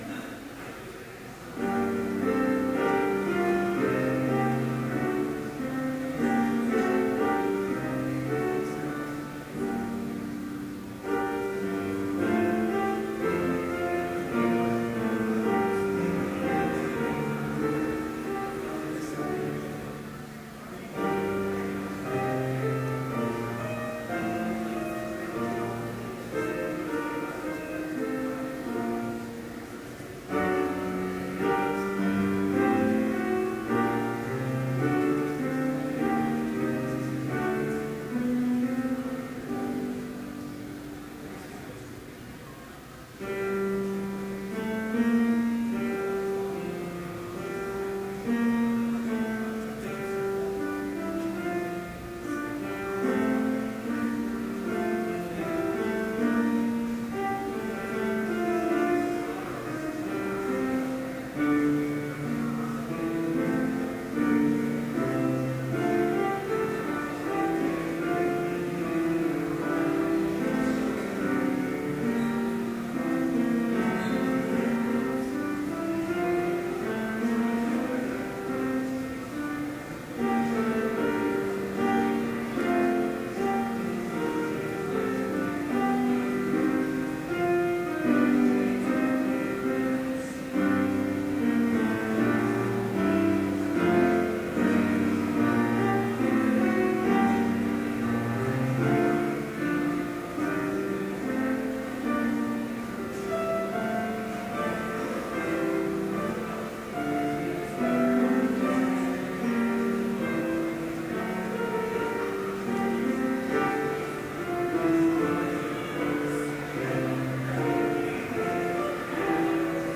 Complete service audio for Chapel - February 8, 2013